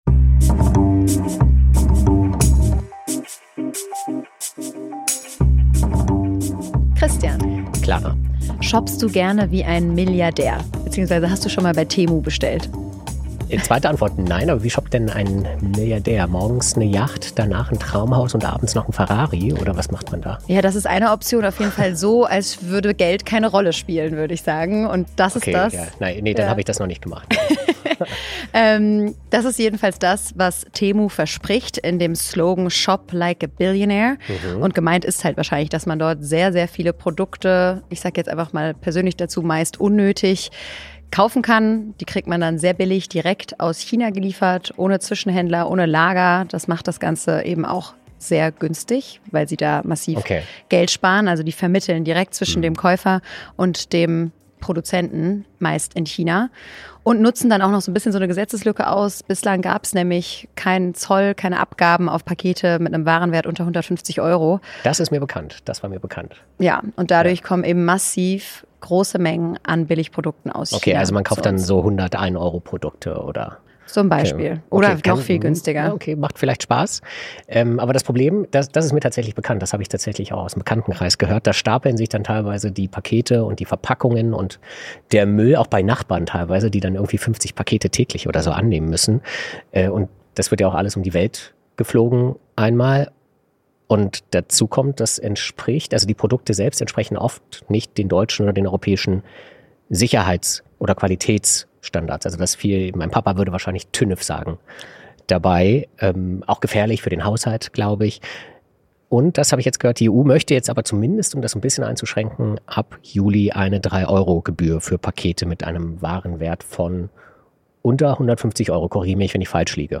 Gast: Carsten Schneider, Bundesminister für Umwelt, Klimaschutz, Naturschutz und nukleare Sicherheit Moderation
Dann bewertet das "Klima-Labor" bei Apple Podcasts oder Spotify Das Interview als Text?